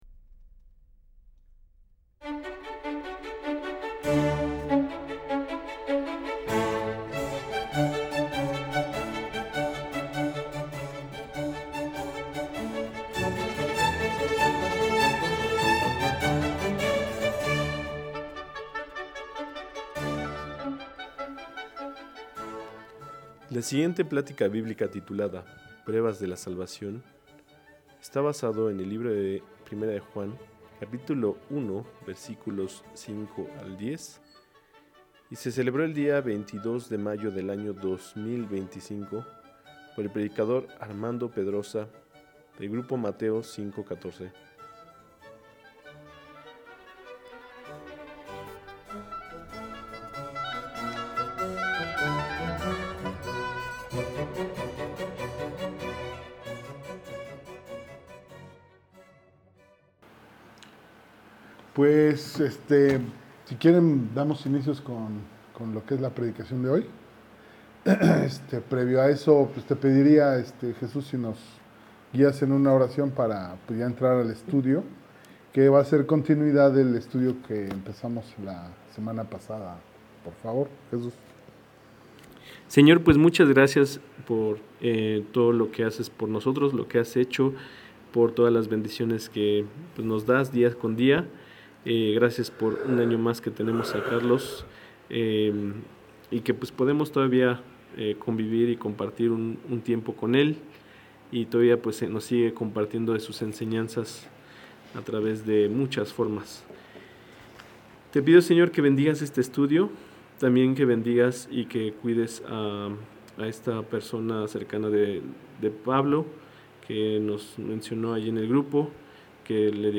2025 Pruebas de la Salvación 1 Preacher